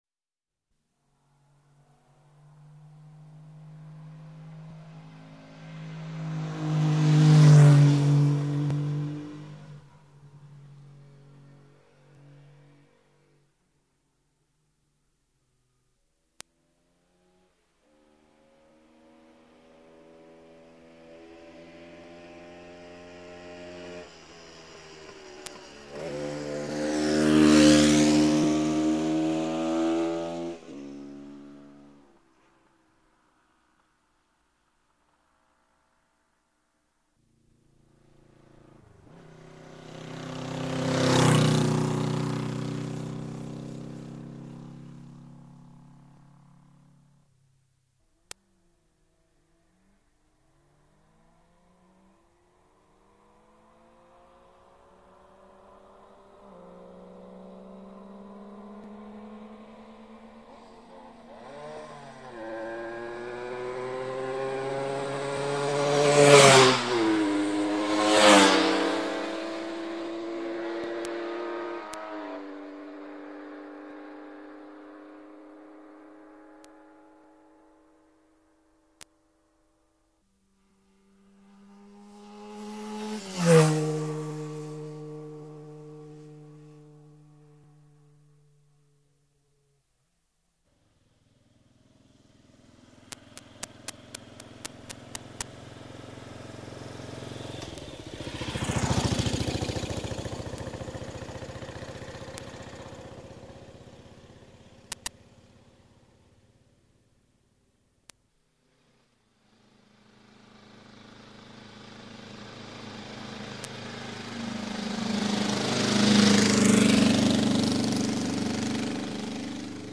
通過音　（レコードの最後に収録されているものです）
５００ＳＳの通過音